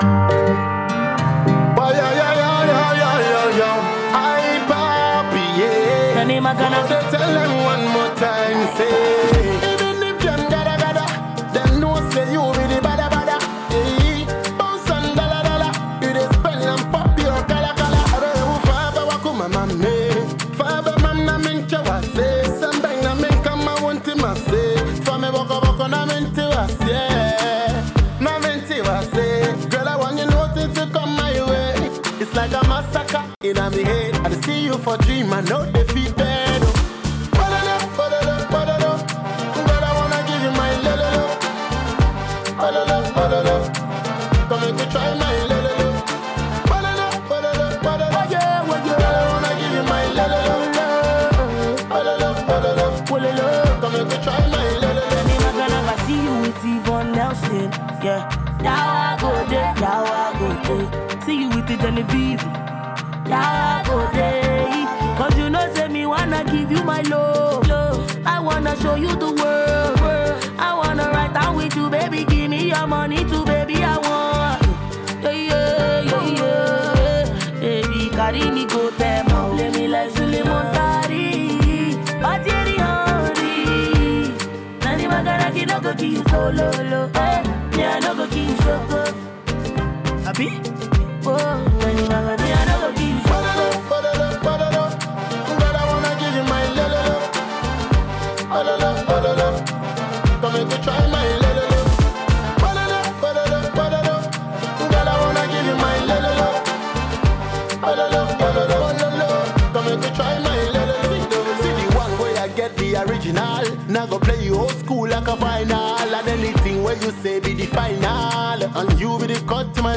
female singer